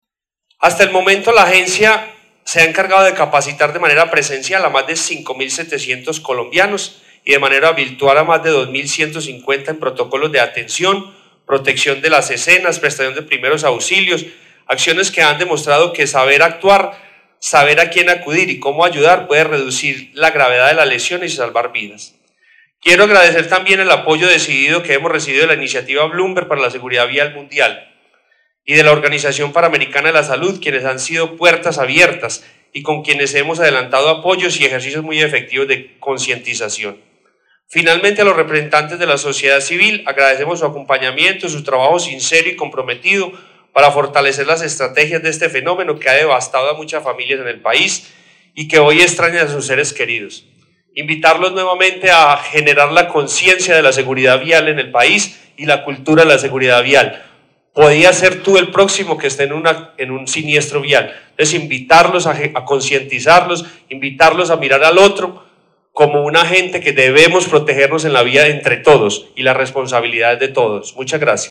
Declaración Juan Carlos Beltrán, director de la Agencia Nacional de Seguridad Vial.